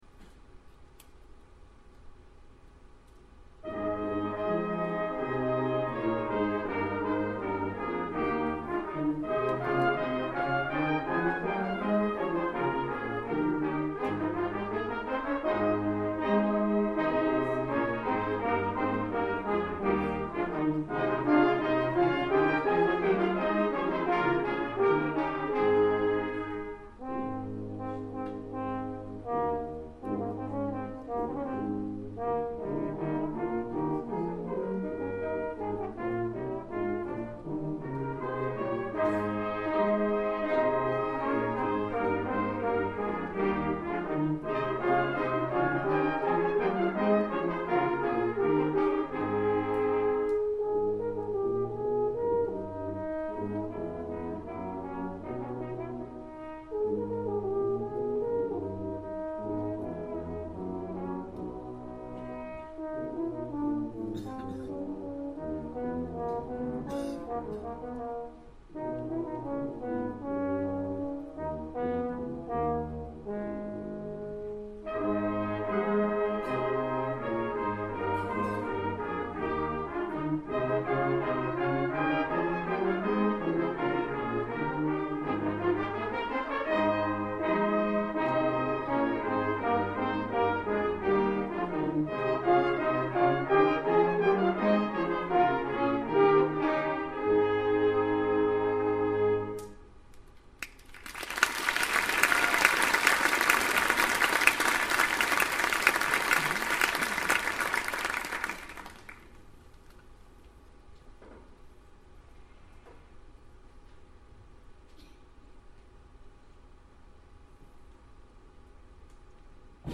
Brass Ensemble